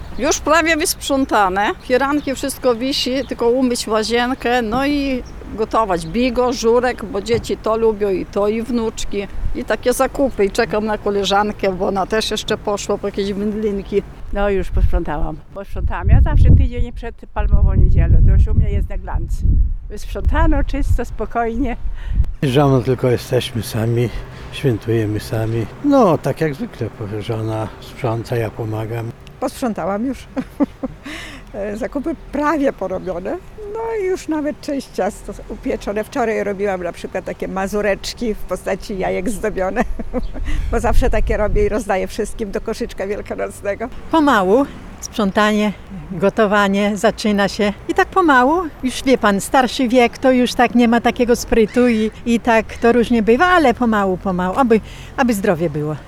Do świąt Wielkanocnych przygotowują się przechodnie zapytani na ulicach Suwałk. Jak wynika z ich wypowiedzi, większość ma już uporządkowane mieszkania, a w najbliższych dniach planuje rozpocząć przygotowywanie tradycyjnych potraw świątecznych.